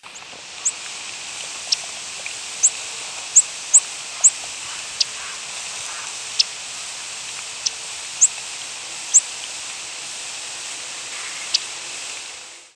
Yellow-rumped Warbler diurnal flight calls
Chip notes of perched "Audubon's" Warbler with blackbirds and various waterfowl calling in the background.